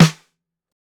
DB SWUNG_SNARE 2.wav